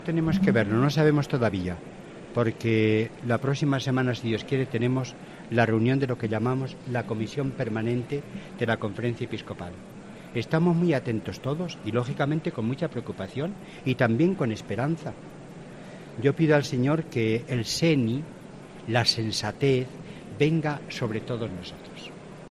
En declaraciones a los periodistas antes de participar en la inauguración del curso académico 2007-2018 de la Universidad Pontificia (UPSA) de la que es Gran Canciller, se ha referido al epitafio que reza en la tumba del expresidente Adolfo Suárez: "la concordia fue posible".